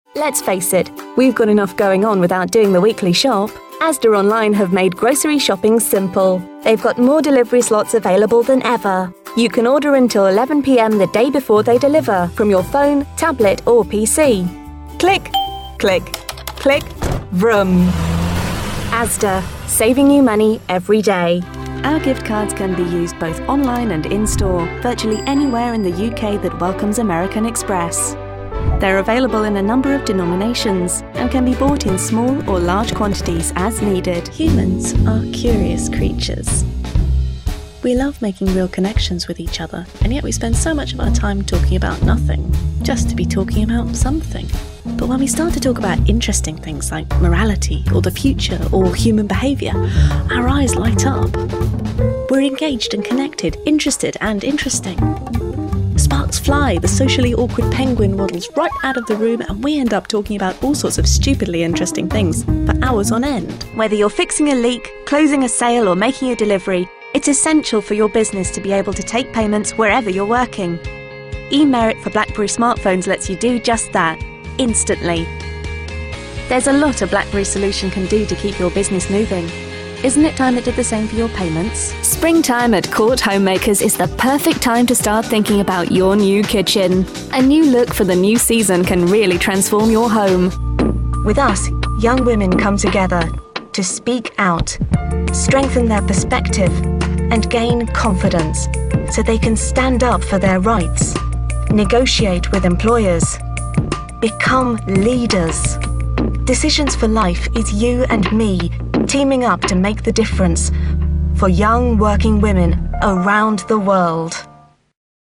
Fresh, bright native British voice over
Sprechprobe: Werbung (Muttersprache):